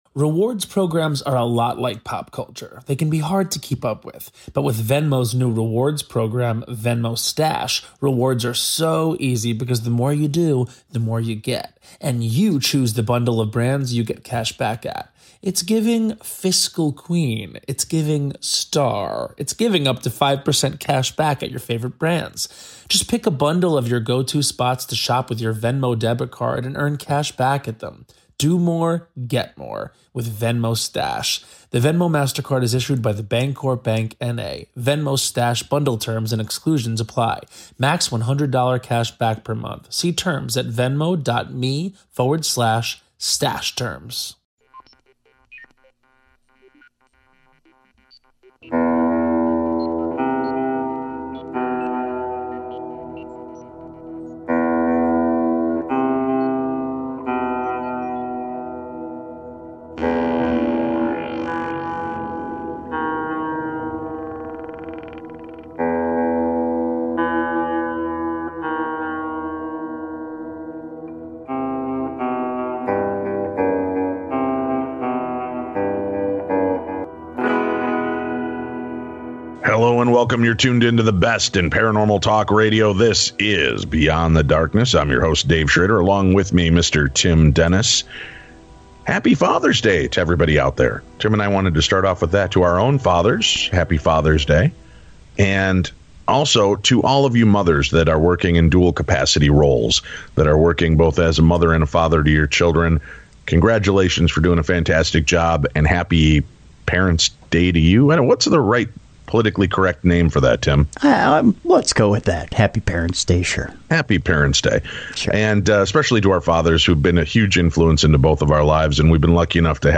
Dark figures, Freaky Phantoms and otherworldly visitations. Today he opens up with us in a candid discussion about these experiences.